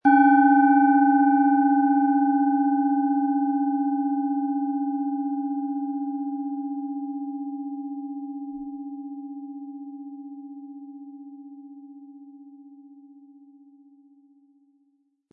Planetenton 1 Planetenton 2
Diese tibetische Planetenschale Pluto ist von Hand gearbeitet.
• Mittlerer Ton: Uranus
Um den Original-Klang genau dieser Schale zu hören, lassen Sie bitte den hinterlegten Sound abspielen.
Den passenden Klöppel erhalten Sie umsonst mitgeliefert, er lässt die Schale voll und wohltuend klingen.
MaterialBronze